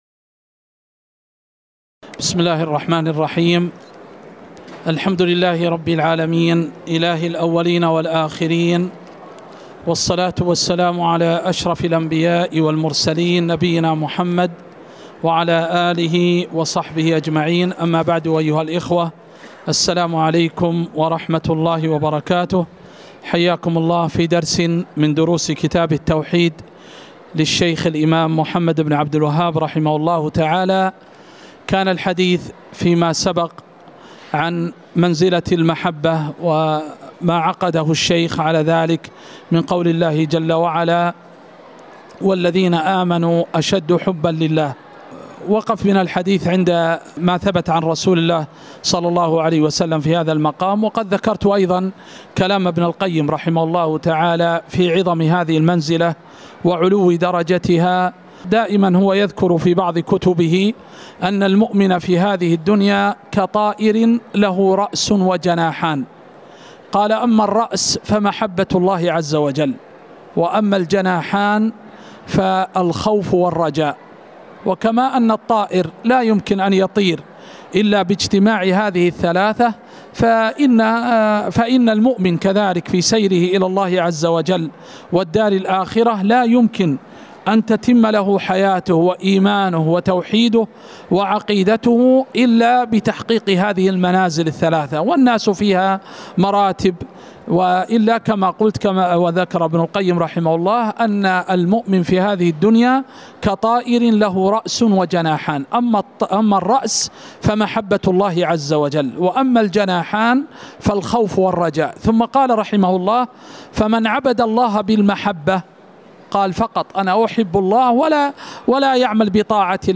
تاريخ النشر ٢٣ شوال ١٤٤٠ هـ المكان: المسجد النبوي الشيخ